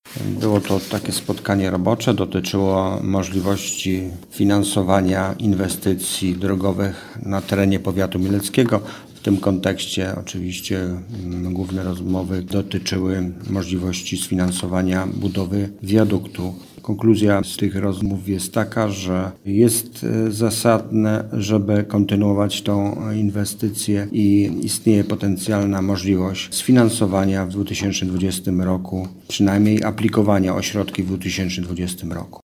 Mówi starosta mielecki Stanisław Lonczak.